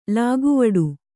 ♪ lāguvaḍu